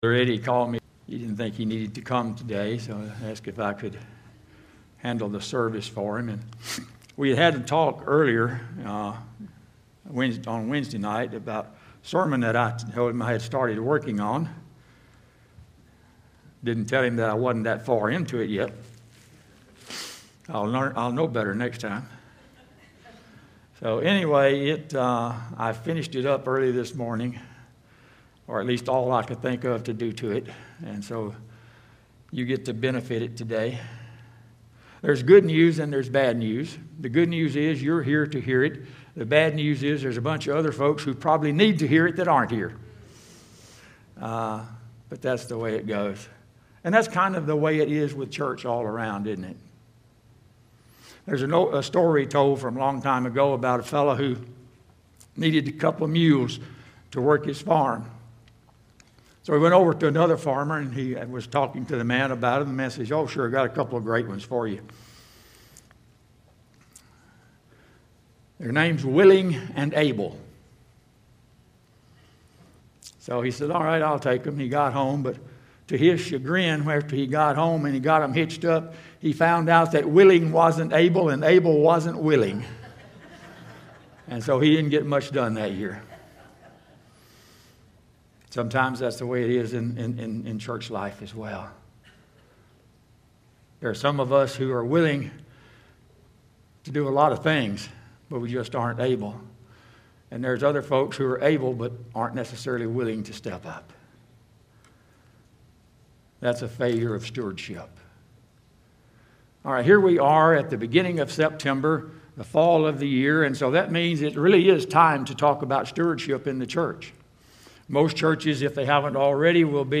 Sermons Archive - GracePoint at Mt. Olive